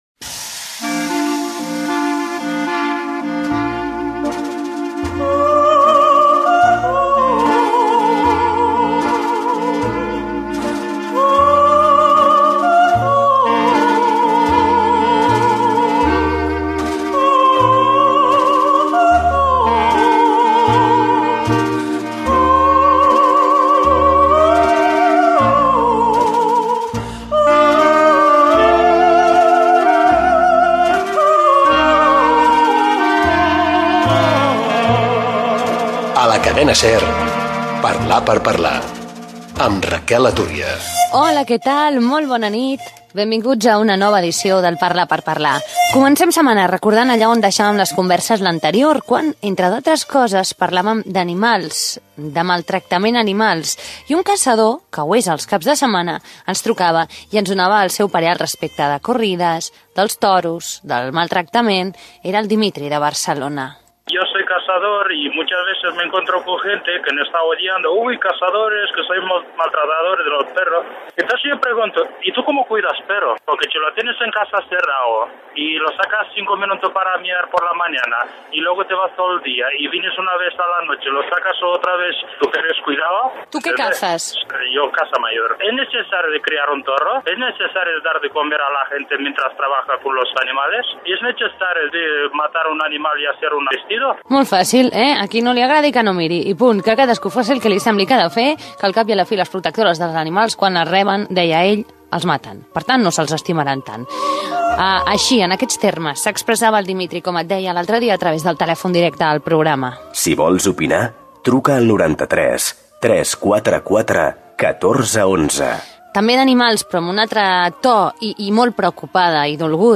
Sintonia, record de'intervencions de la setmana passada sobre el maltractament animal, telèfon de participació, una trucada sobre la mort d'un gat, telèfon del programa i contacte per les xarxes socials